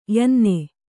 ♪ yanne